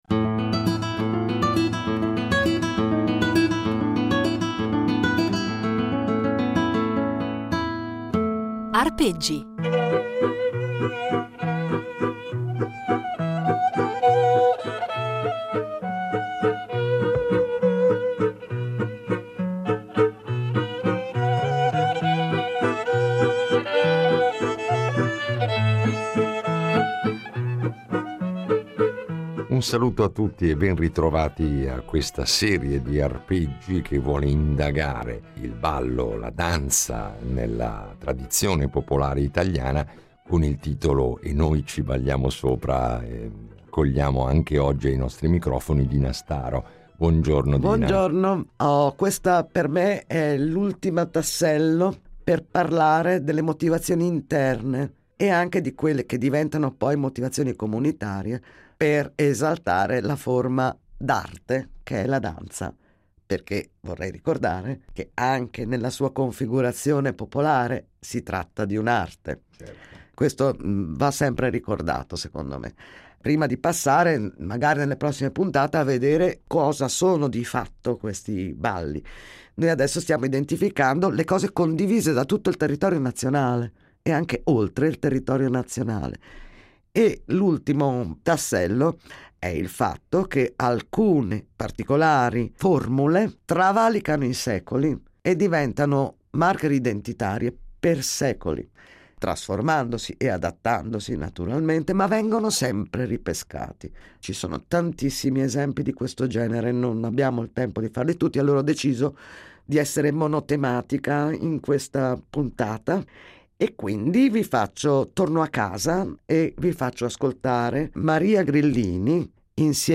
Un itinerario sonoro ricco di materiale inedito, registrato sul campo e negli anni da lei stessa, e illuminato da esempi che ci propone dal vivo, con la sua voce e il suo violino e accompagnata dalle percussioni di